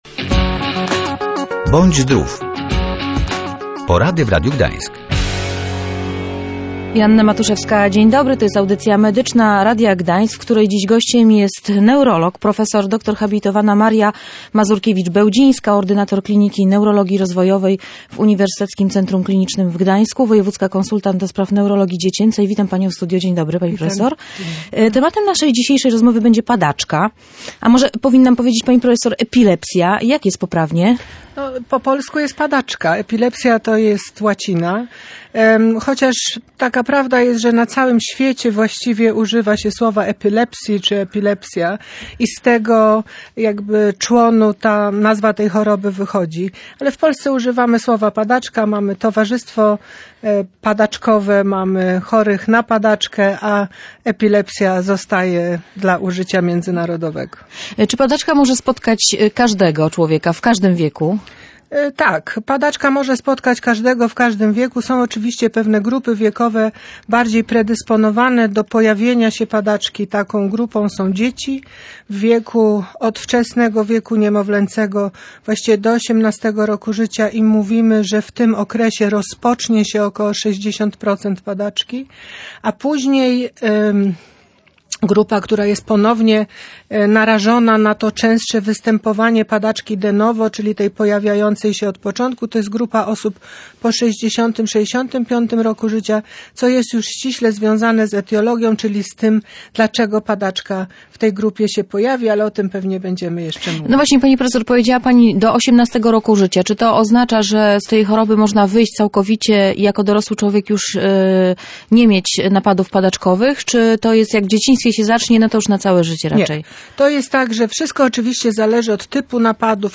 – Apeluję, żeby ich nie stygmatyzować – mówiła w Radiu Gdańsk prof. dr hab.